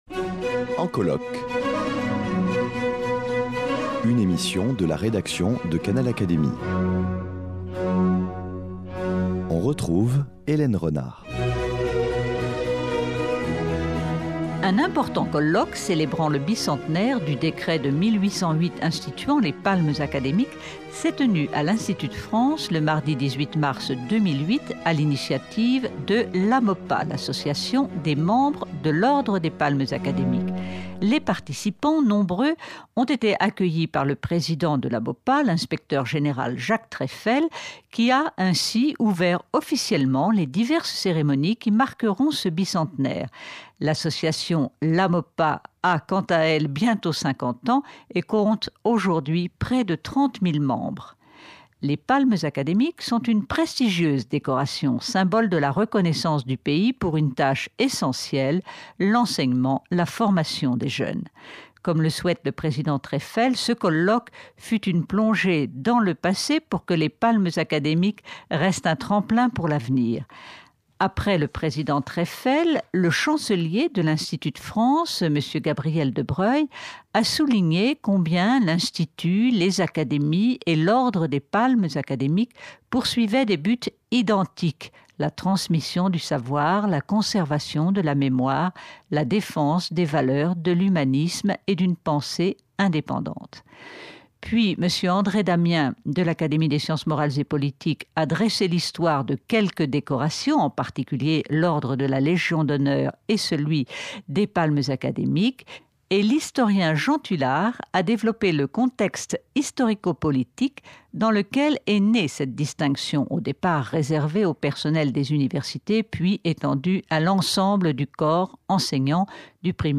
Un colloque organisé par l’AMOPA (Association des Membres de l’Ordre des Palmes Académiques) a célébré le bicentenaire du décret de 1808 instituant cette distinction. Jean Tulard, dans son intervention, rappelle le contexte politique et souligne l’organisation de l’enseignement voulue par l’Empereur.